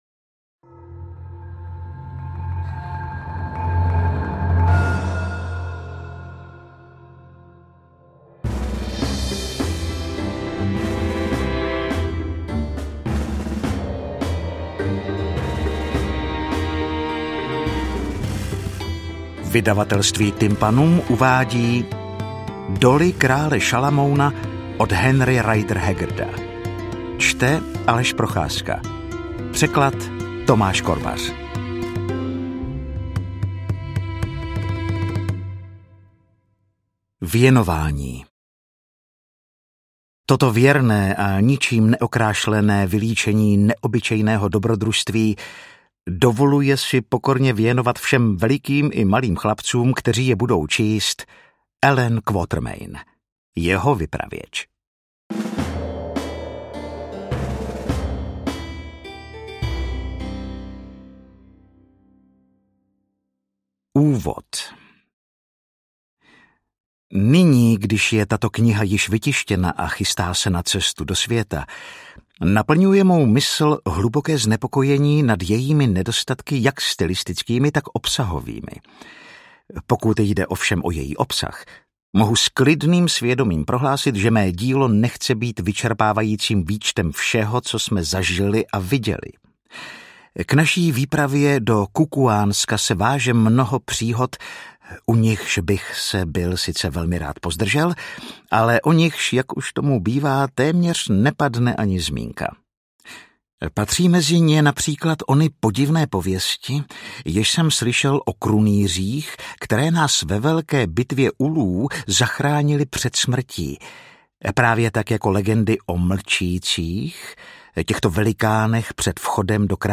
Interpret:  Aleš Procházka
AudioKniha ke stažení, 27 x mp3, délka 9 hod. 58 min., velikost 547,3 MB, česky